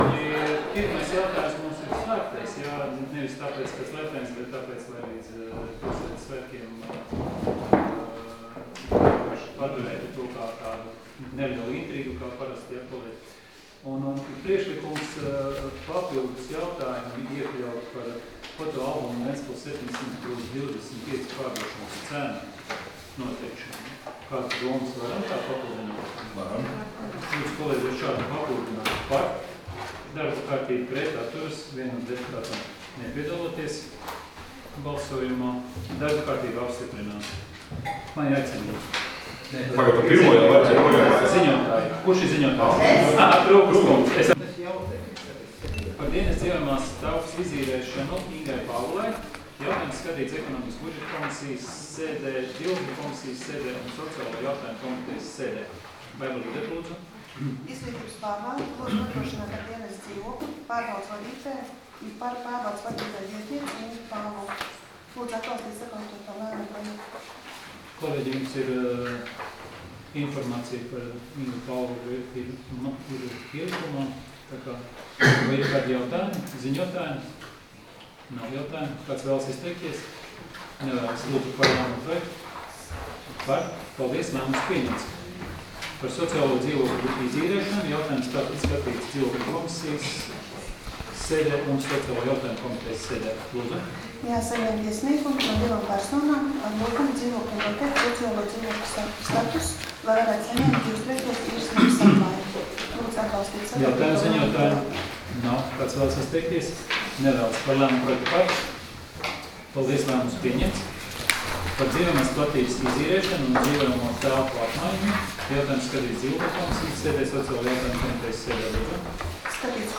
Domes sēdes 16.07.2015. audioieraksts